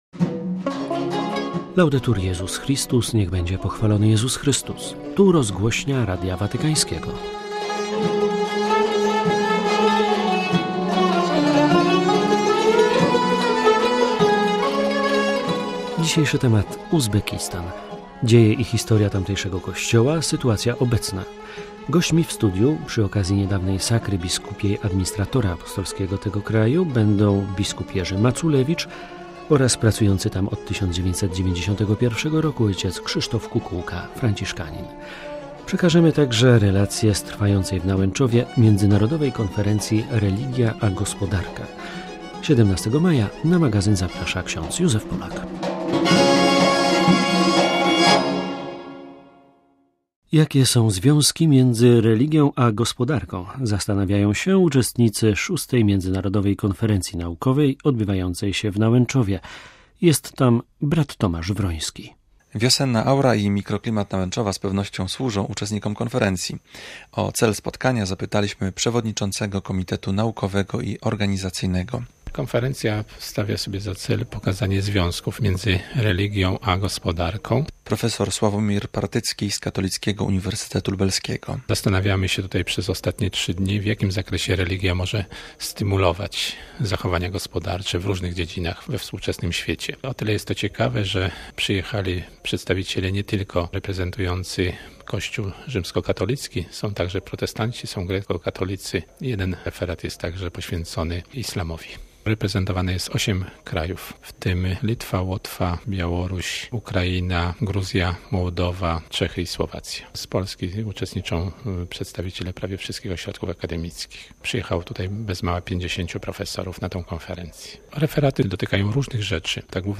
relacja z trwającej w Nałęczowie międzynarodowej konferencji „Religia a gospodarka”; - gośćmi w studiu